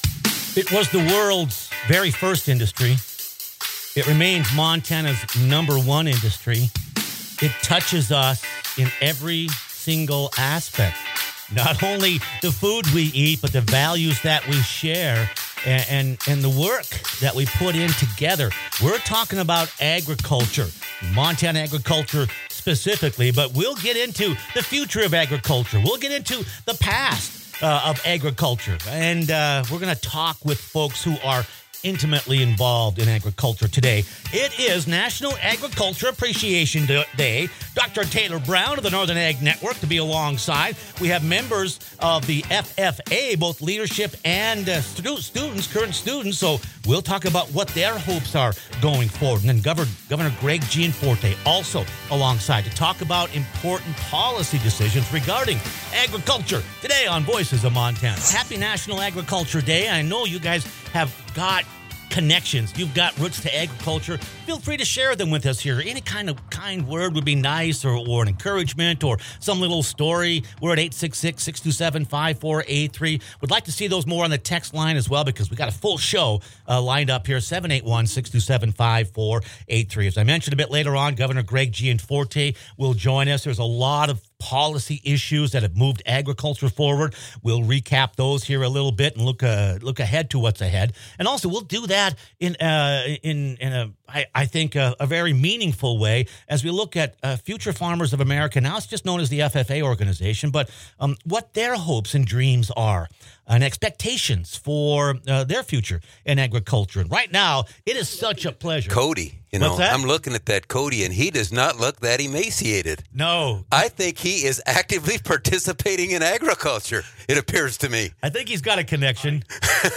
Voices of Montana celebrates National Agriculture Appreciation Day with a special program highlighting Montana’s number one industry. Longtime farm broadcaster, rancher, and former legislator Dr. Taylor Brown kicked-off the show looking at the evolving challenges and opportunities in agriculture. Montana FFA leaders share how young people are preparing for careers in the industry, and Governor